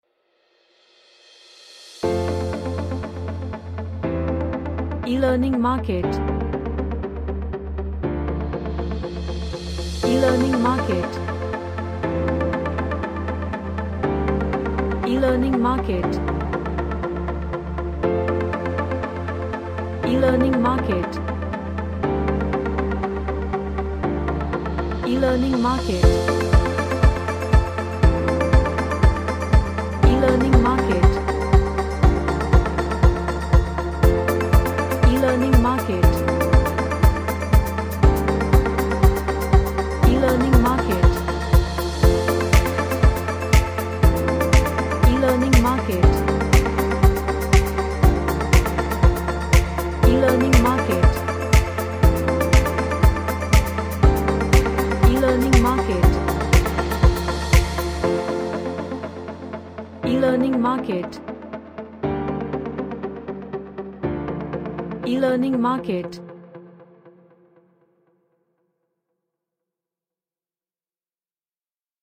A Corporate track in Major Scale.
Happy